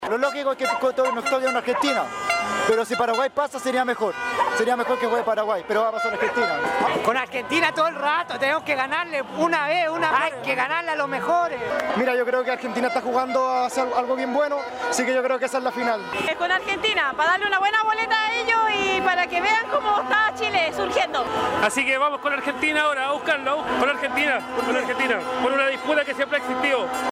Concluido el cotejo, Osorno vivió la hasta ahora más multitudinaria celebración en la Plaza de Armas de la ciudad desde que vio su inicio el campeonato el pasado 11 de junio, con una incesante caravana de vehículos que se sumó a las cerca de 2 mil 500 personas que festejaron con cánticos, vuvuzelas e incluso fuegos artificiales que estallaron en tres ocasiones en los cielos osorninos.
Así se vivió el festejo en Osorno